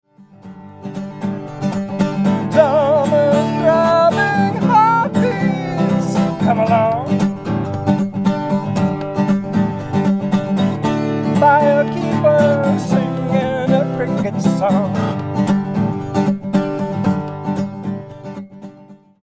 Multi-track CD Recorded in
the studio and Digitally Mastered.